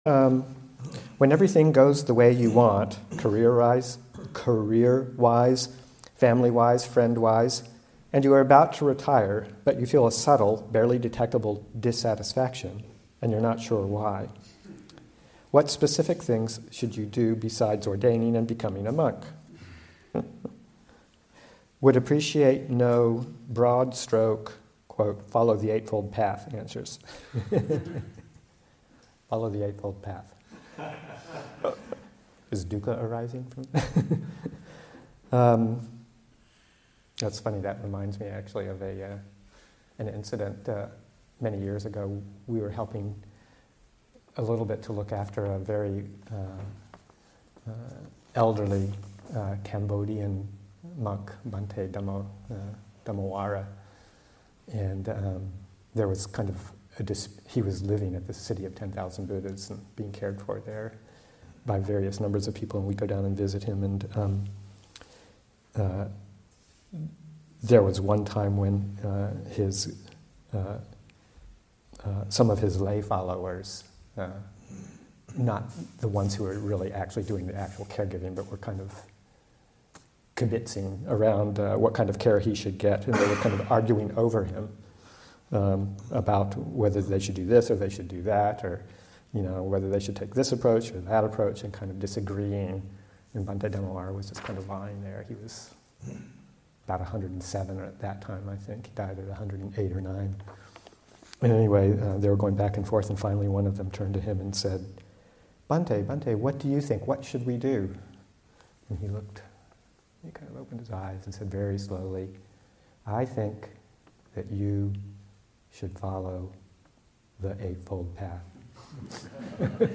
Interreligious Retreat-Seminar on Dhamma and Non-duality [2023], Session 4, Excerpt 1.1